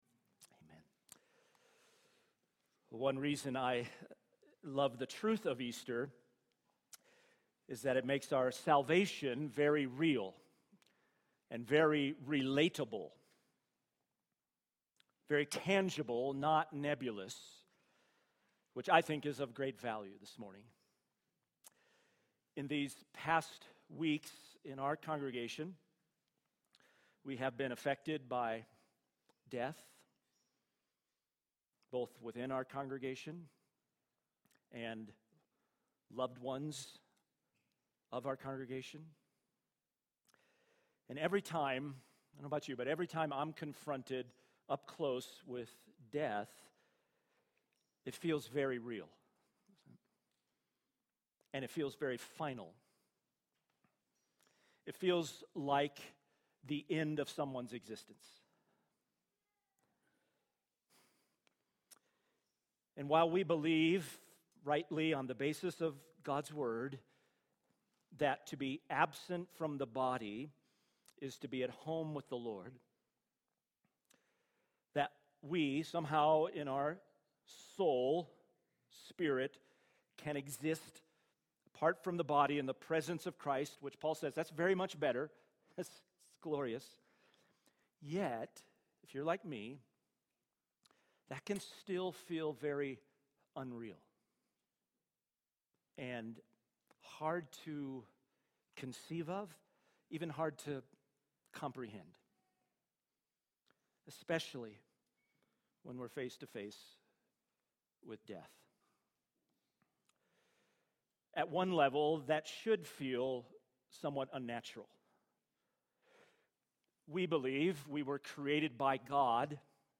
SERMON – Crossroads Bible Church